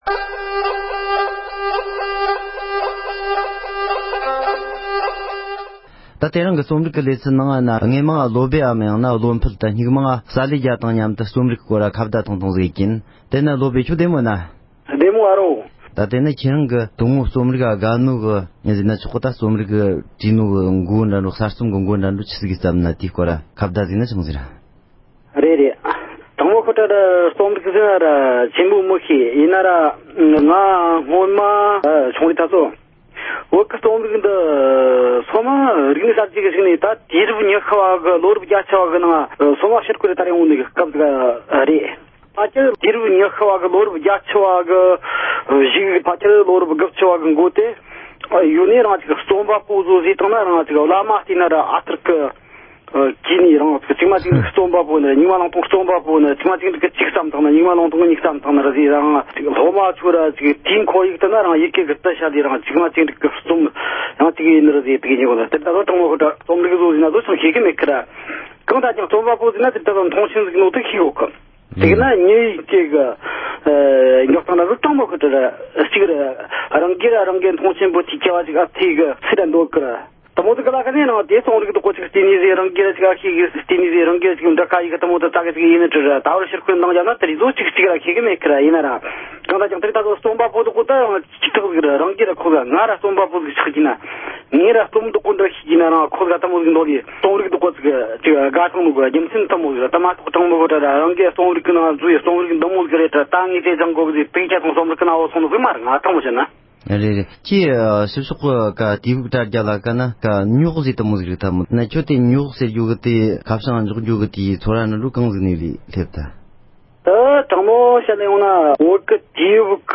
རྩོམ་རིག་གི་སྐོར་གླེང་མོལ།